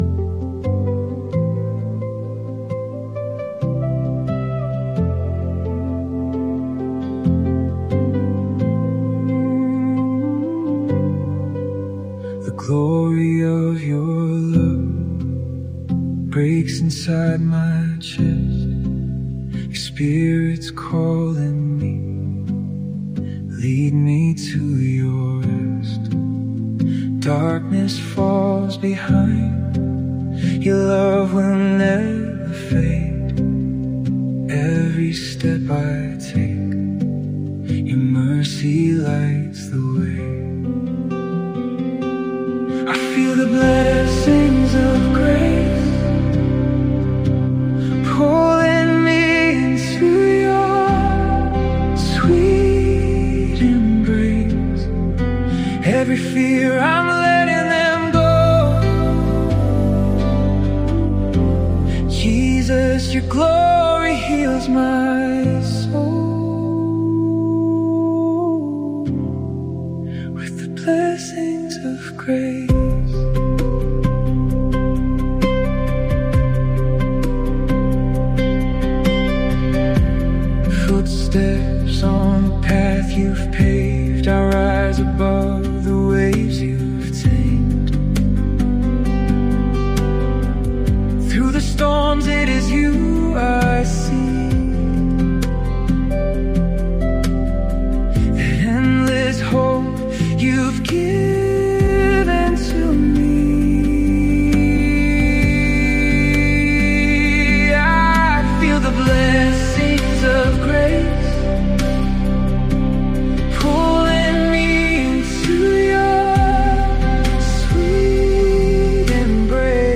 Christian